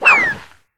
refereePig_fall_01.ogg